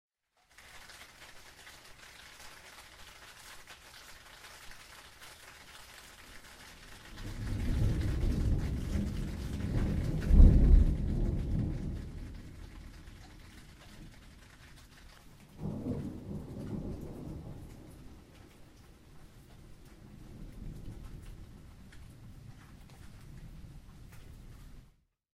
Berlin rain
Rain and thunder, recorded on a balcony in the southern quarters of Berlin Germany